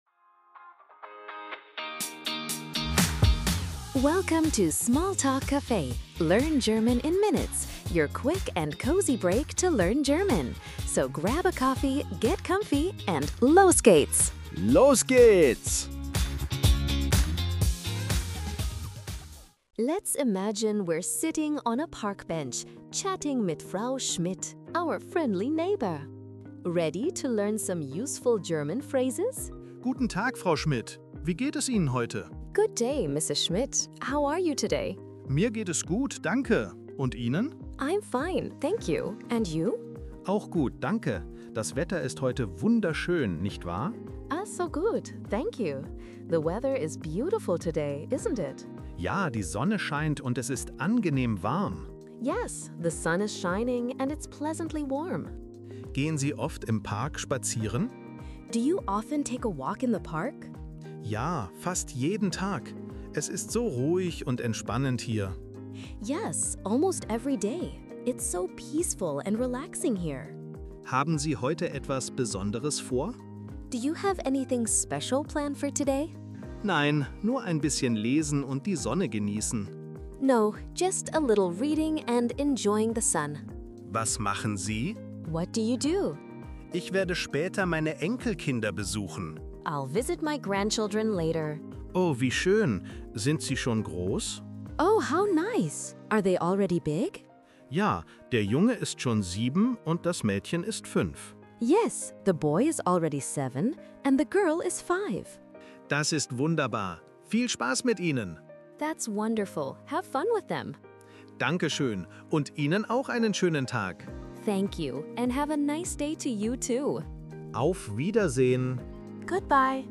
Enjoy a relaxing chat on a park bench.